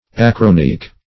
Search Result for " acronyc" : The Collaborative International Dictionary of English v.0.48: Acronyc \A*cron"yc\, Acronychal \A*cron"ych*al\, a. [Gr.